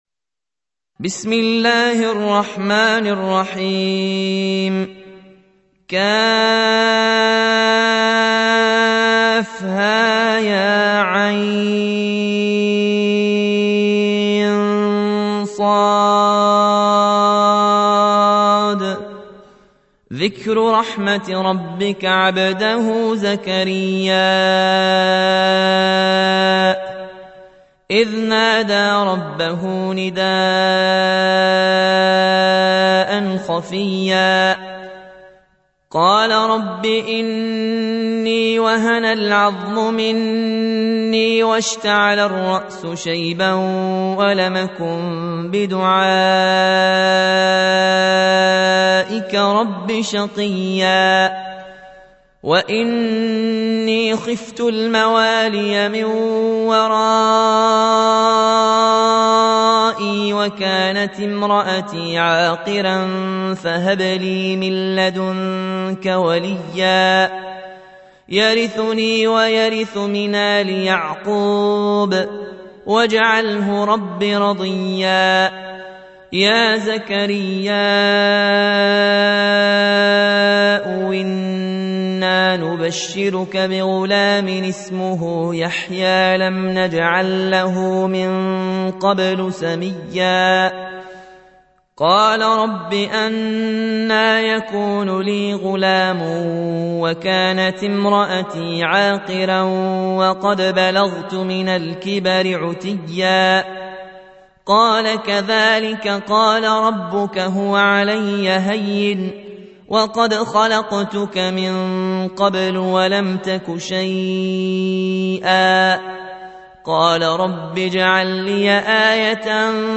19. سورة مريم / القارئ